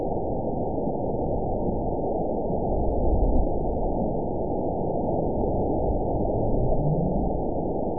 event 913778 date 04/19/22 time 22:32:39 GMT (3 years, 1 month ago) score 9.35 location TSS-AB04 detected by nrw target species NRW annotations +NRW Spectrogram: Frequency (kHz) vs. Time (s) audio not available .wav